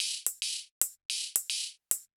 Index of /musicradar/ultimate-hihat-samples/110bpm
UHH_ElectroHatA_110-04.wav